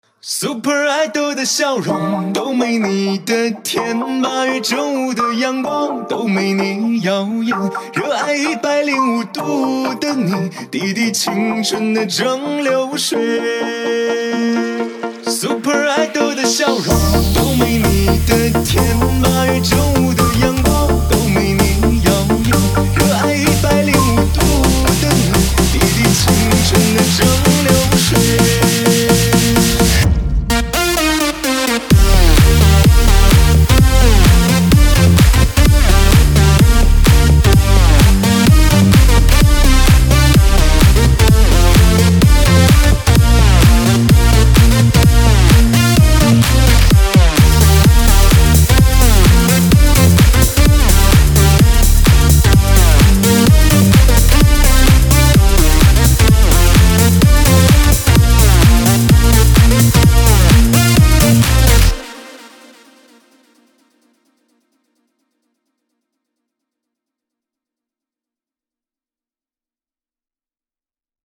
Music / House